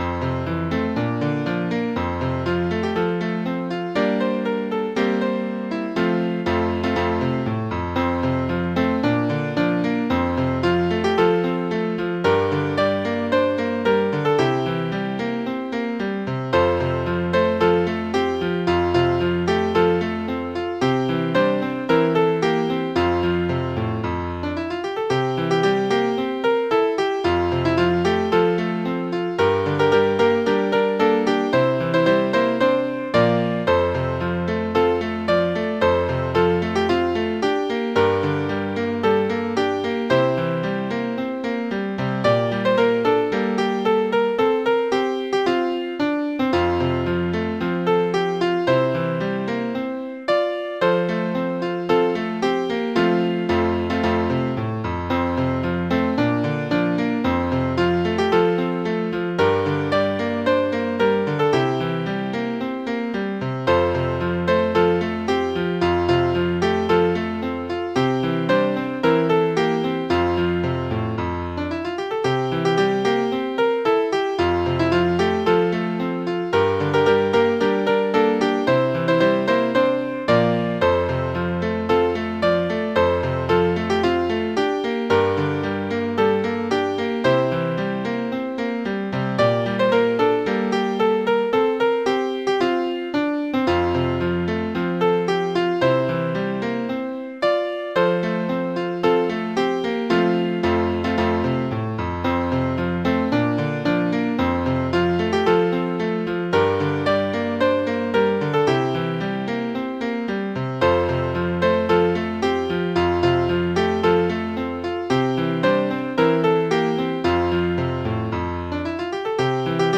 オリジナル/６和音